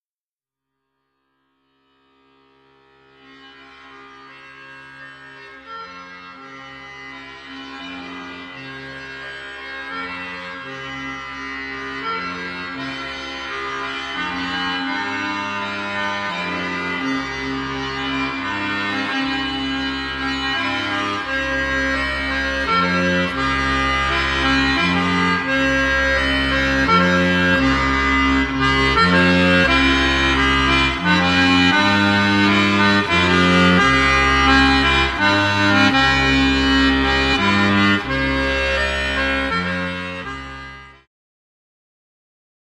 skrzypce
akordeon, flety proste, whistles, cytra
mandolina, gitara akustyczna